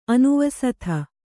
♪ anuvasatha